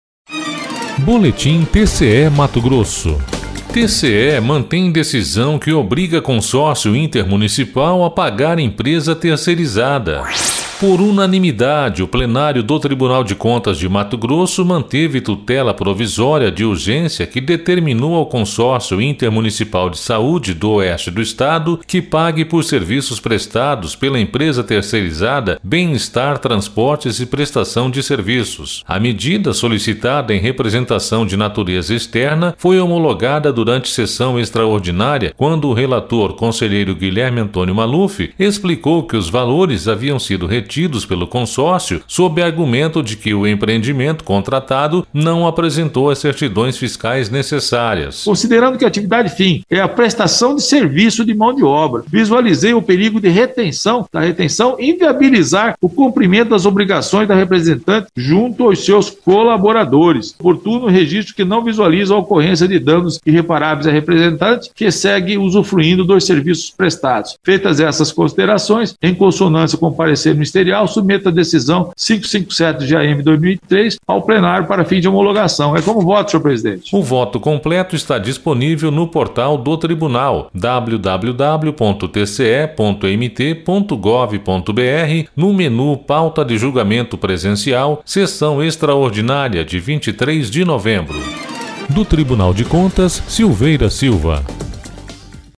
Sonora: Guilherme Antonio Maluf – conselheiro do TCE-MT